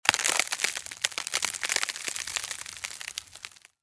冰冻道具.mp3